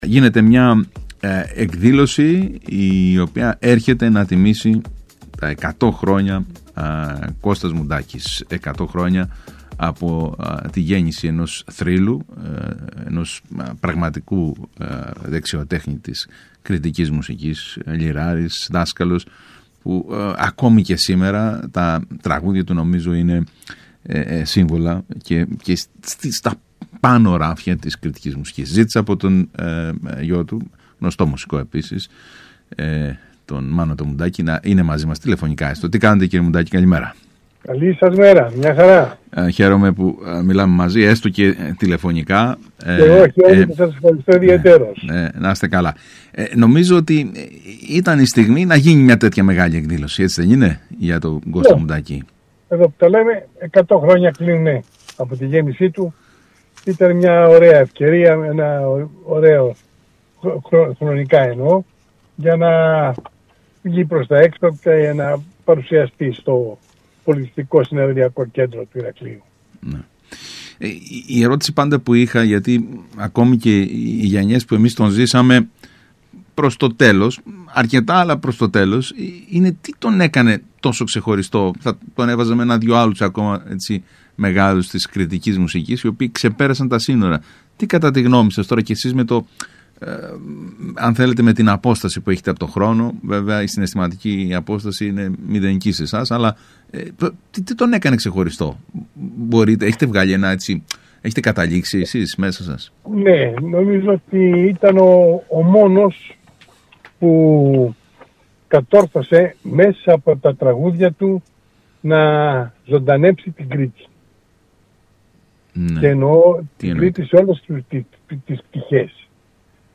Ακούστε το ηχητικό απόσπασμα από τον ΣΚΑΙ Κρήτης 92,1: